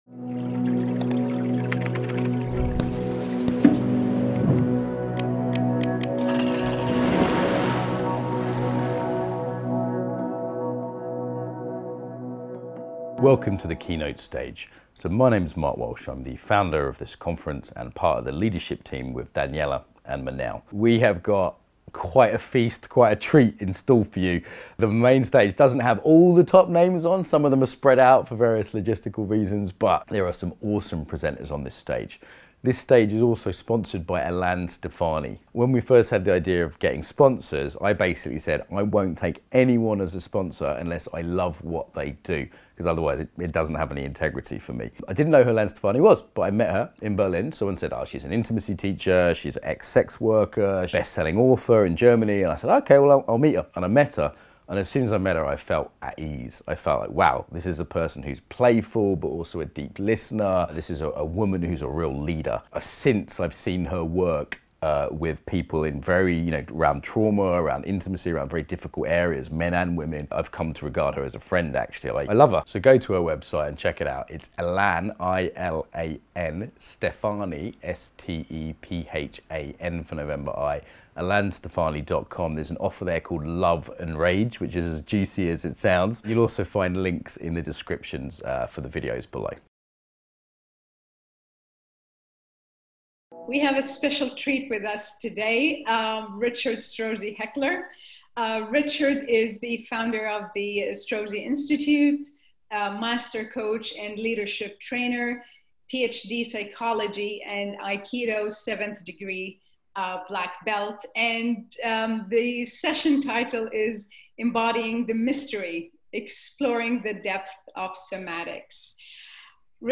This session will explore the maturation of somatics in the West and the role Spirit plays in the embodiment domain. There will be practices, time for questions and answers, and a sincere inquiry into what is the future of somatics.